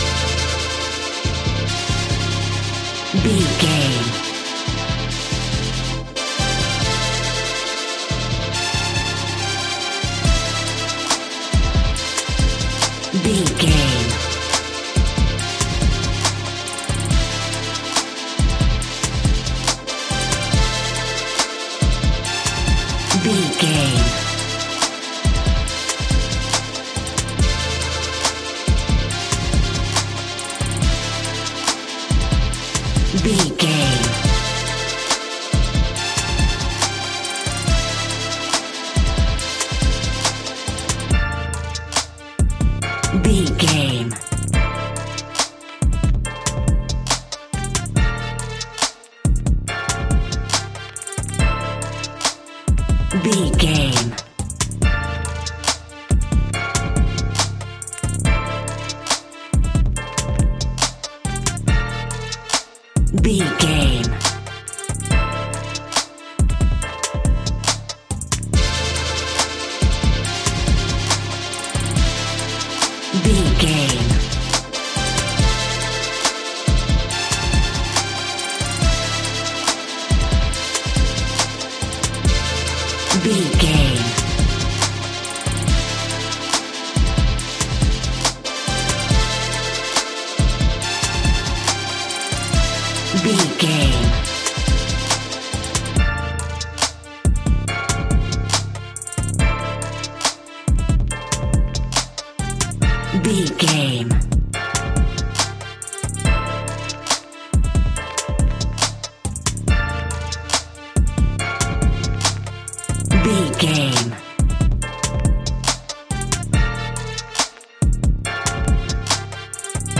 Aeolian/Minor
Fast
bright
dreamy
relaxed
tranquil
serene